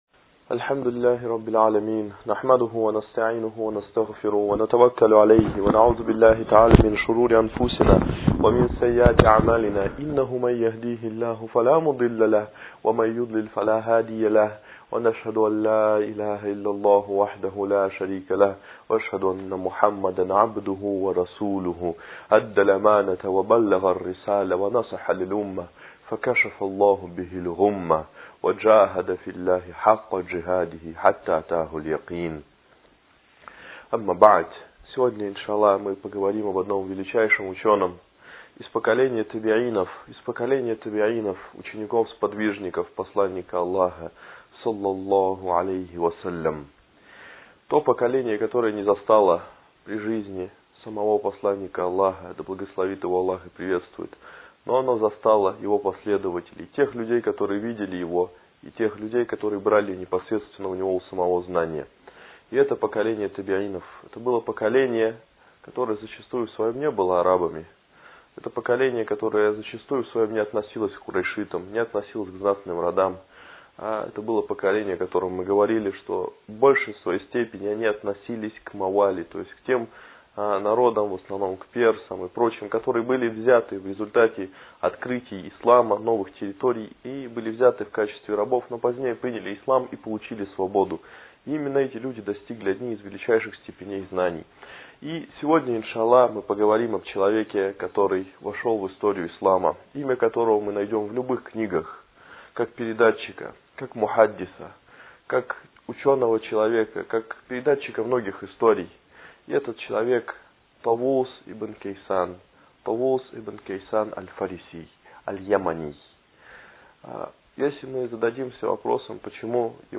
Лекции о праведных предках. Эта лекция о ученом из поколения Табиинов, Тавусе ибн Кисане, да смилуется над ним Аллах.